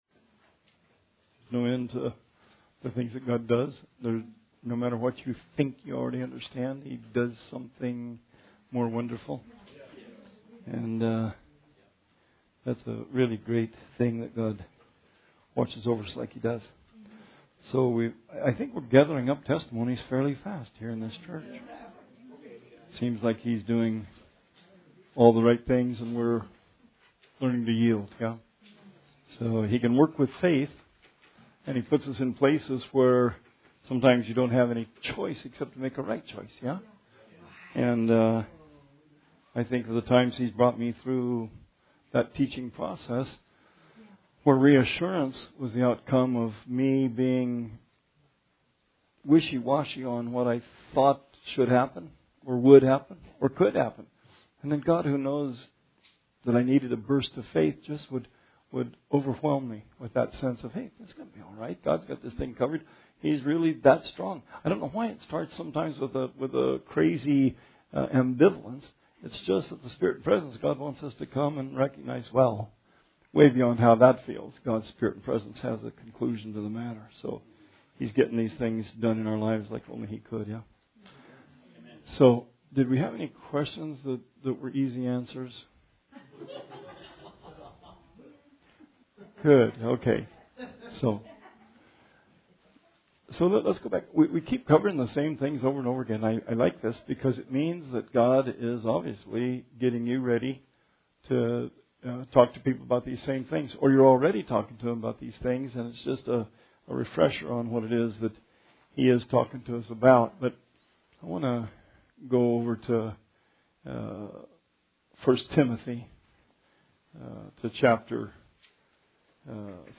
Bible Study 2/19/20 – RR Archives